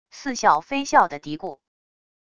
似笑非笑的嘀咕wav音频